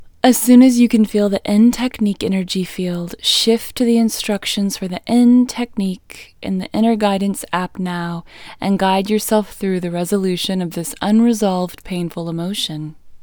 LOCATE IN English Female 38